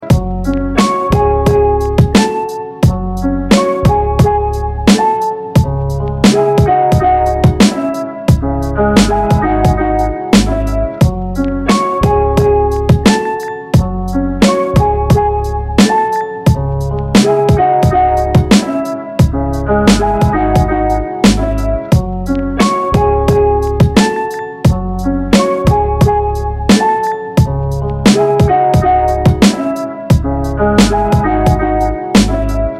• Качество: 256, Stereo
русский рэп
без слов
instrumental hip-hop
минус
Самодельная инструменталка песни